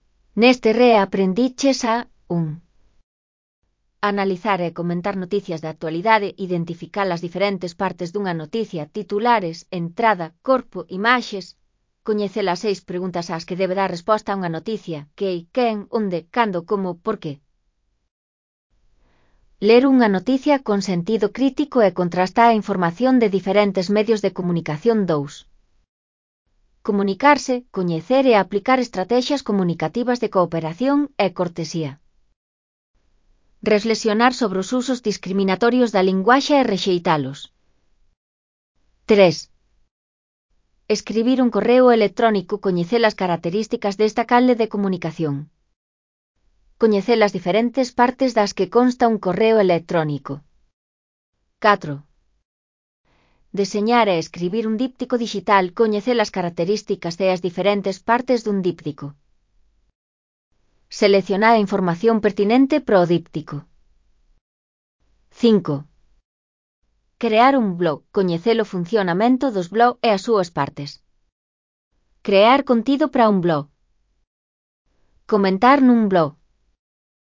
Elaboración propia (Proxecto cREAgal) con apoio de IA, voz sintética xerada co modelo Celtia. Que aprendiches? (CC BY-NC-SA)